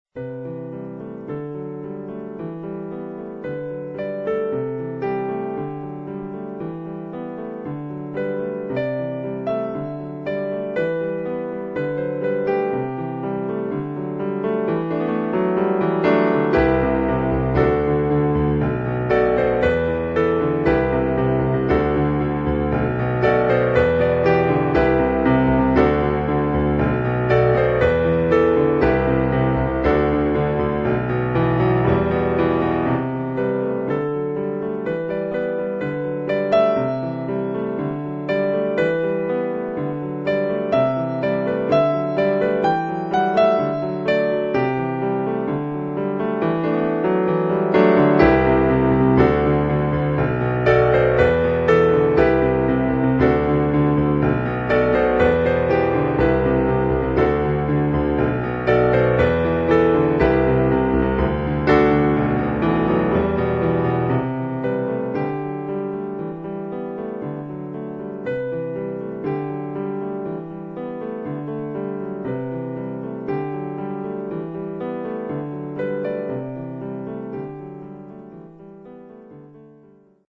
Some pleasant, musically vapid crap today. Probably simple and stupid enough to be made into a popular song.